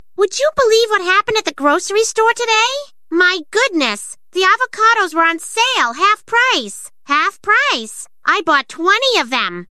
Quirky Female English_OneShot.MP3